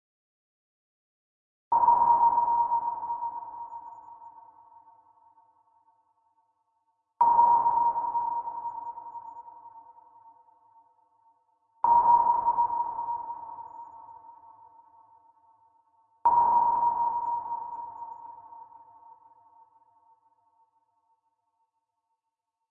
Звуки эхолота
Эхолот подводной лодки издает характерный звук при сканировании дна